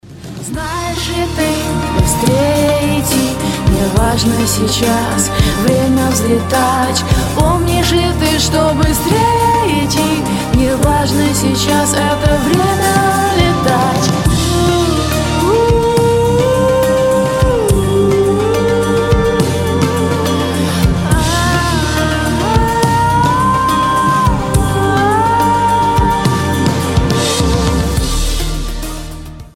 • Качество: 256, Stereo
поп
женский вокал